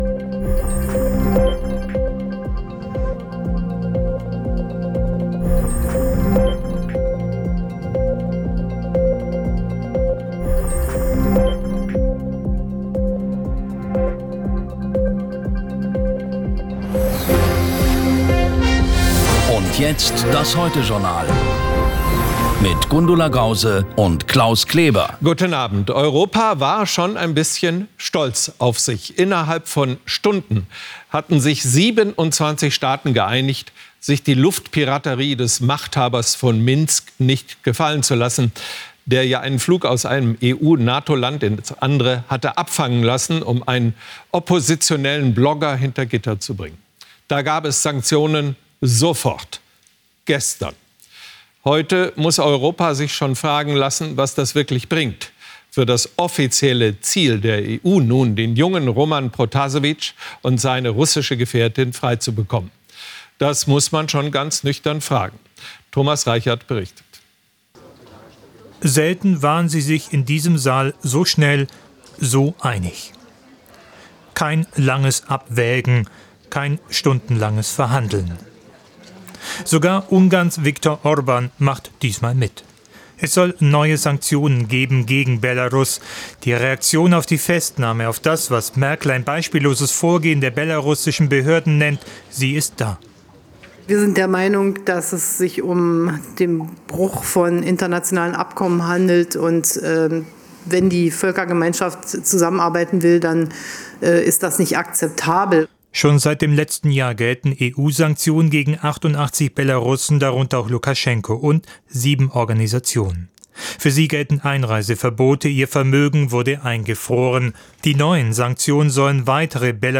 Politische Berichte, scharfsinnige Analysen, verständliche Erklärungen